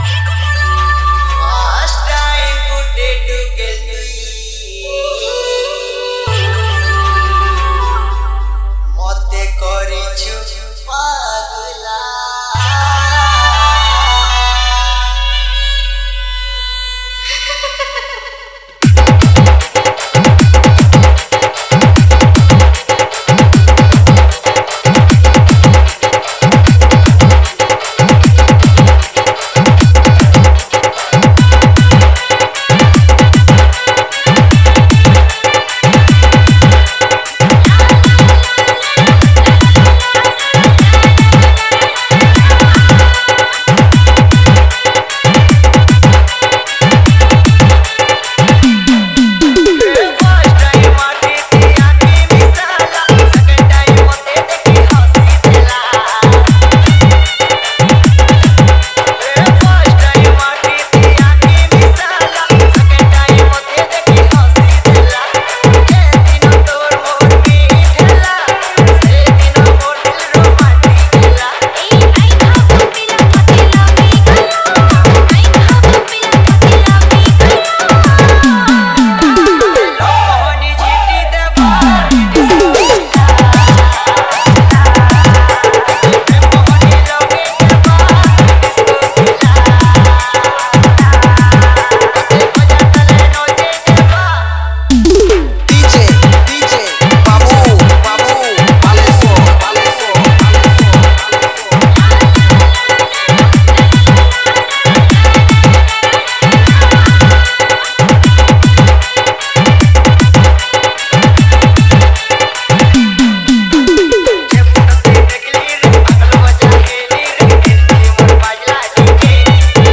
Category:  Sambalpuri Dj Song 2021